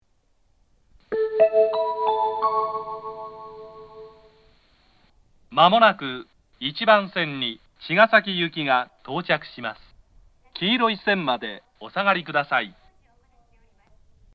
東海道型（男性）
接近放送
茅ヶ崎行の接近放送です。
接近チャイムはあまり聞かないタイプのものです。